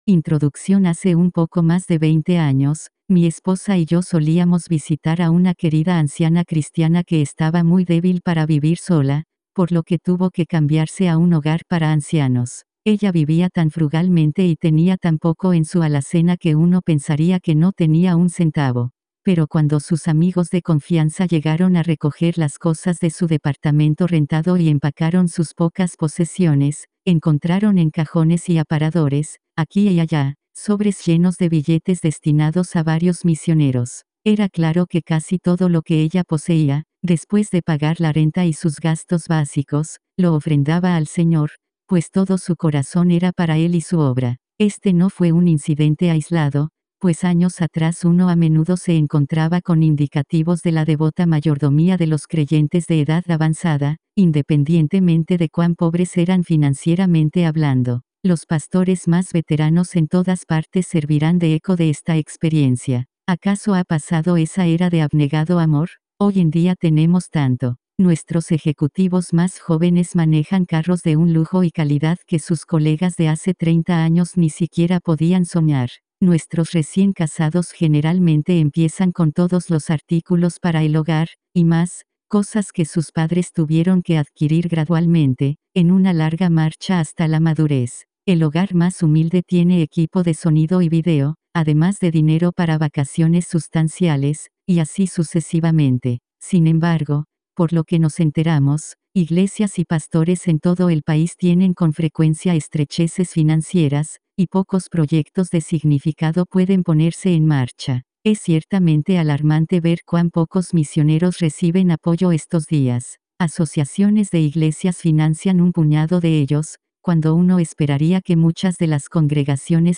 Audio Libro